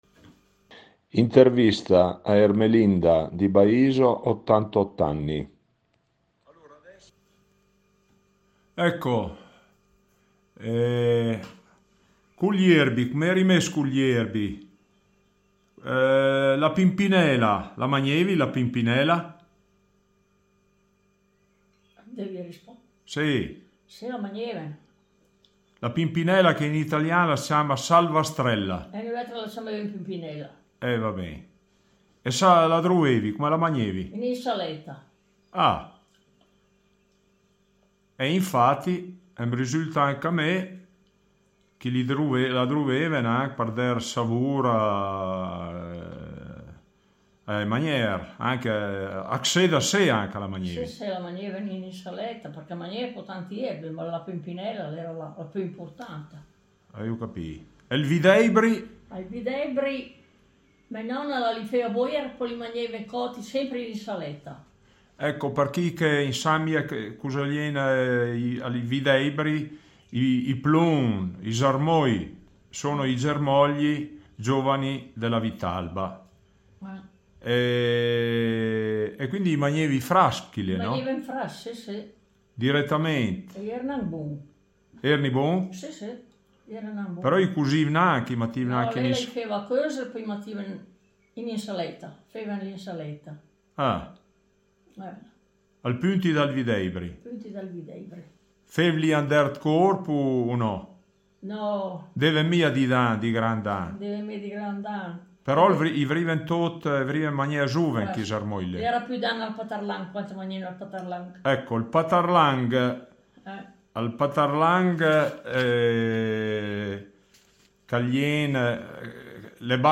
Colloquio